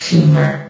CitadelStationBot df15bbe0f0 [MIRROR] New & Fixed AI VOX Sound Files ( #6003 ) ...